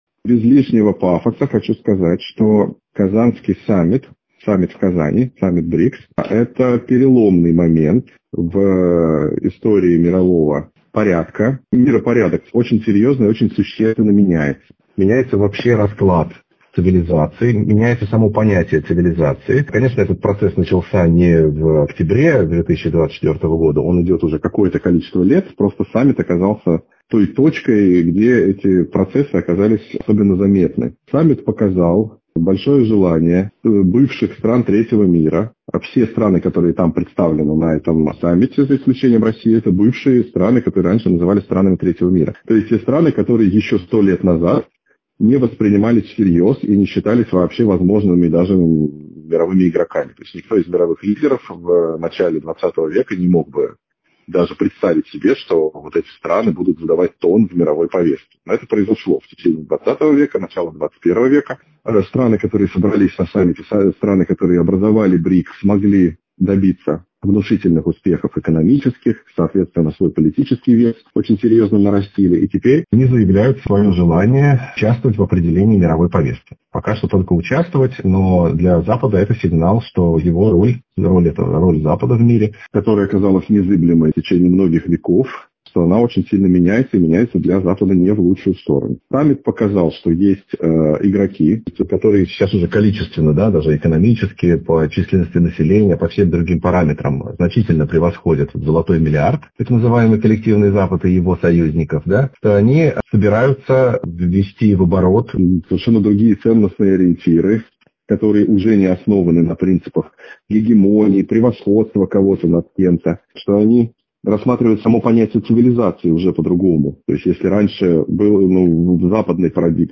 аудиоверсия программы